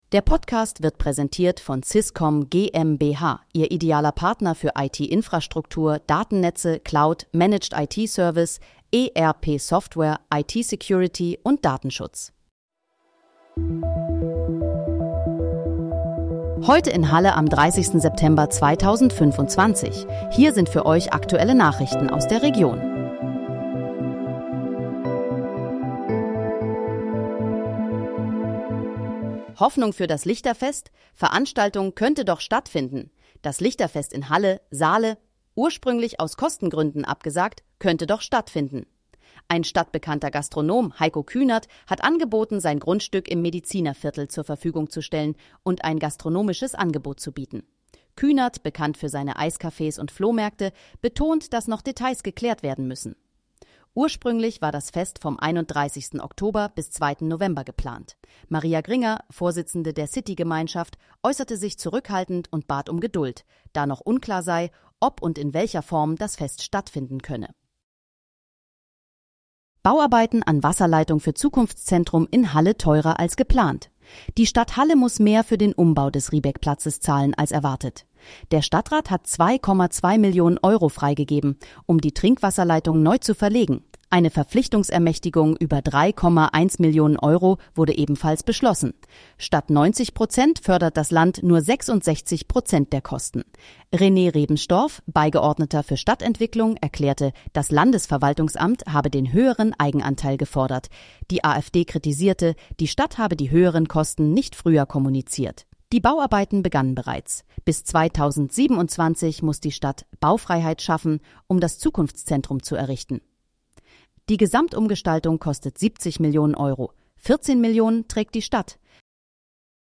Heute in, Halle: Aktuelle Nachrichten vom 30.09.2025, erstellt mit KI-Unterstützung
Nachrichten